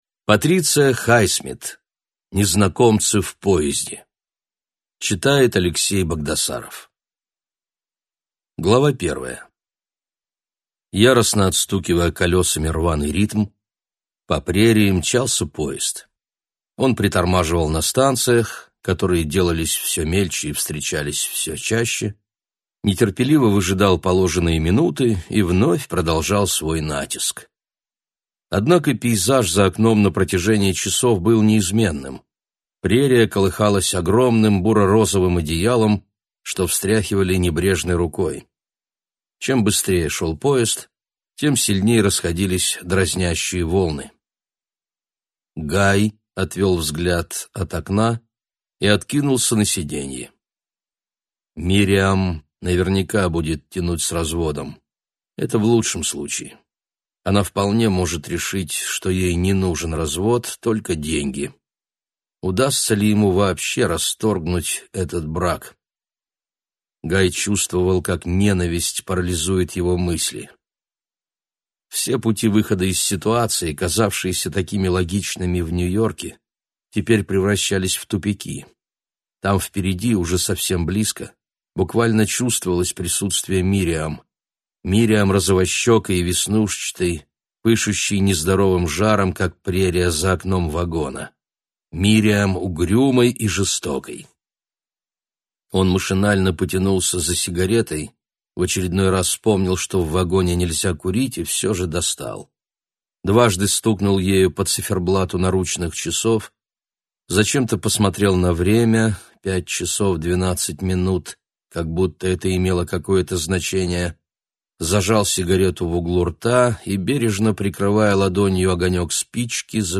Аудиокнига Незнакомцы в поезде | Библиотека аудиокниг